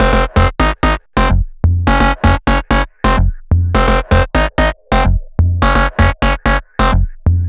VEE Melody Kits 33 128 BPM Root F.wav